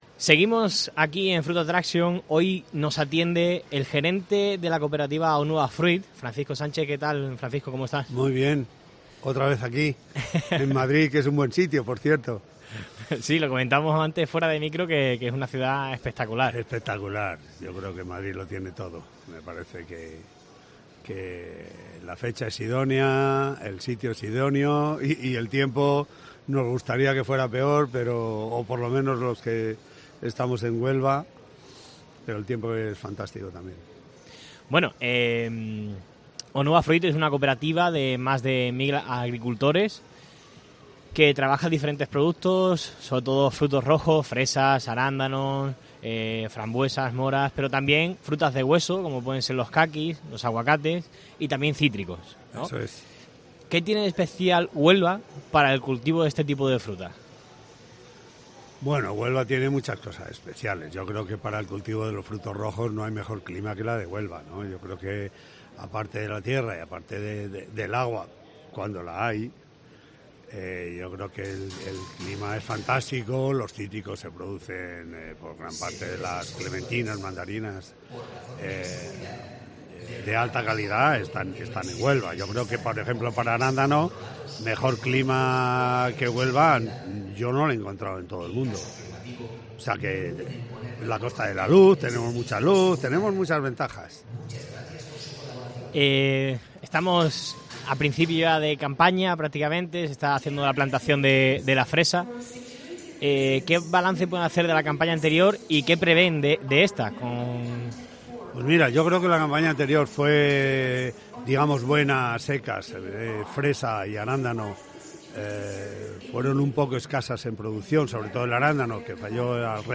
Fruit Attraction 2023 | Entrevista
repasa en los micrófonos de COPE Huelva los asuntos de actualidad del sector de los frutos rojos desde la feria Fruit Attraction.